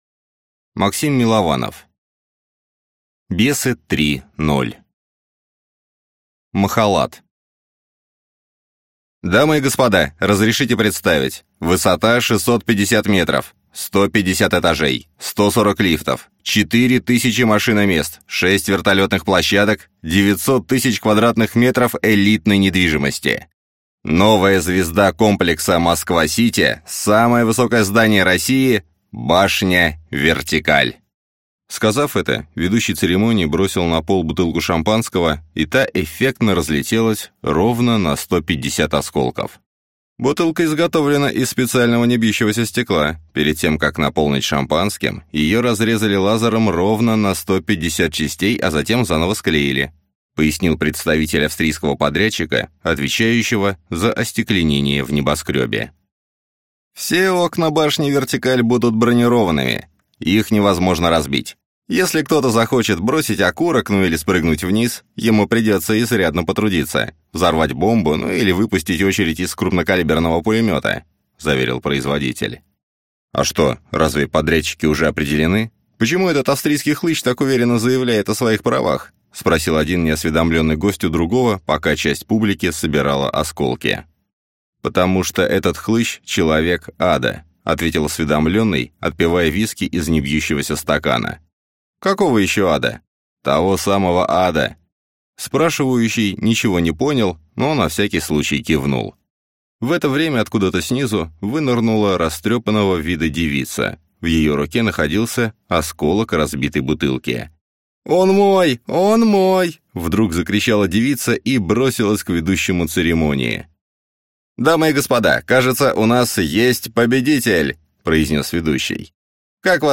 Аудиокнига Бесы 3.0 | Библиотека аудиокниг